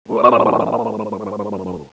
headshak.wav